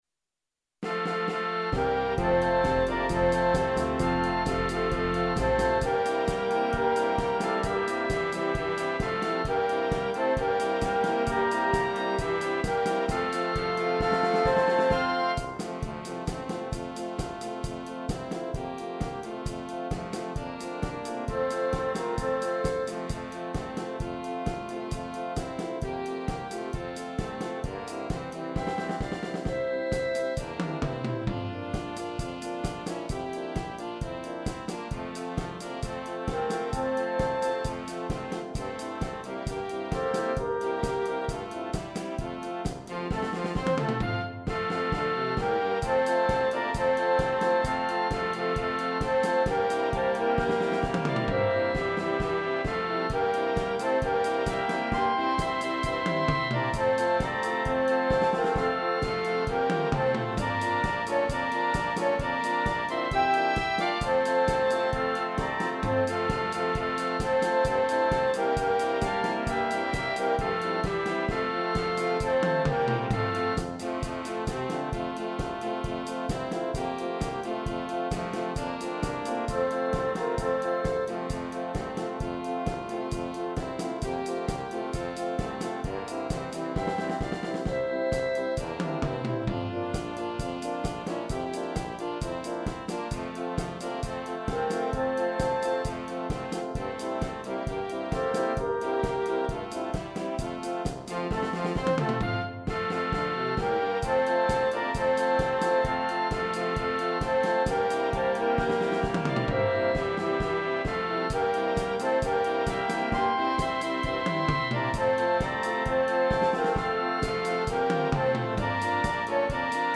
Blasmusik im Party-Sound    !
Für Blasorchester mit Gesang!